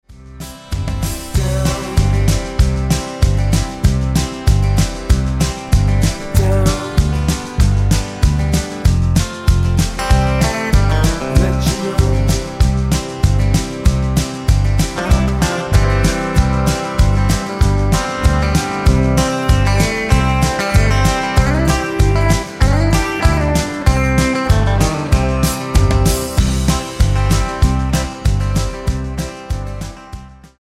--> MP3 Demo abspielen...
Tonart:C mit Chor